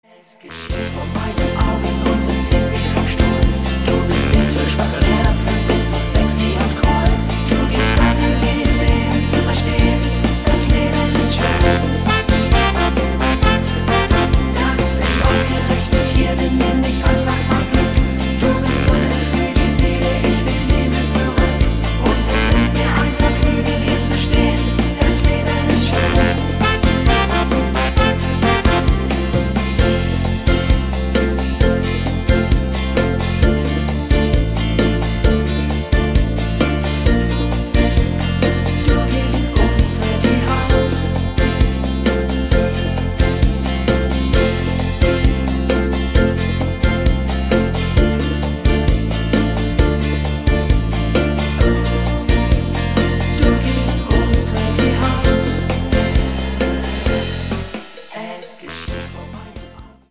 sehr guter Rhythmus